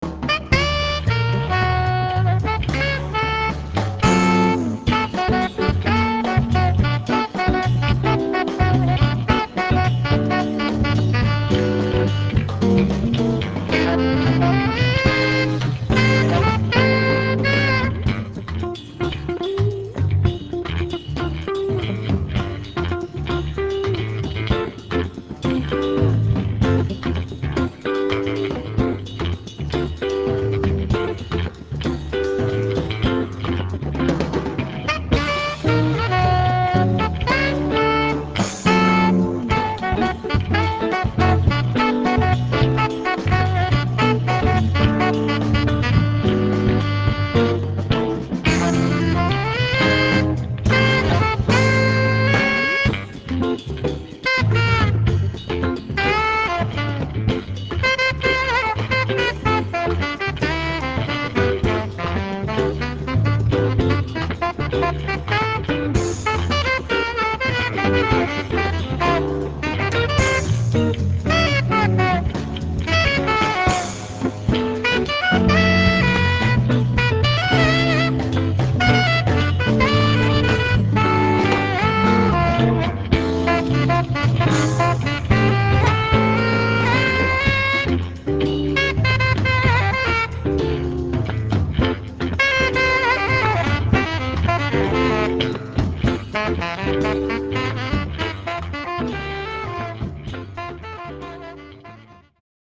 alto saxophone
guitar
bass
drums